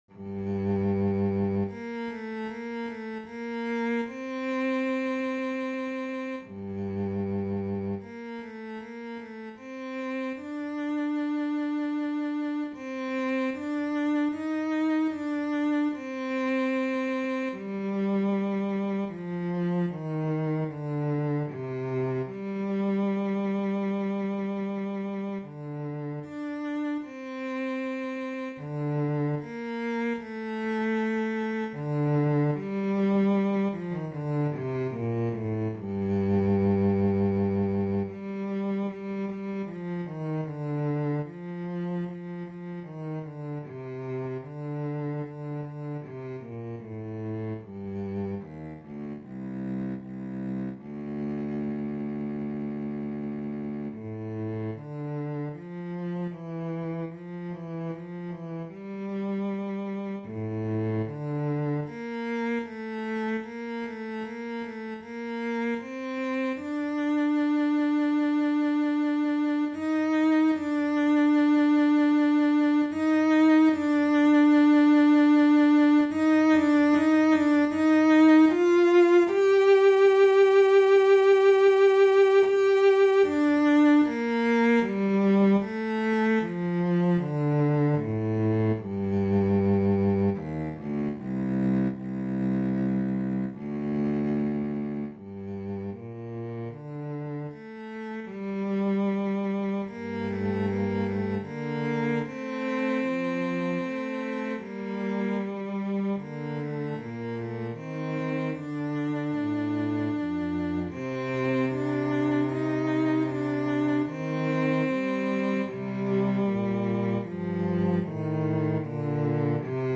a composition for two cellos
Computer rendition.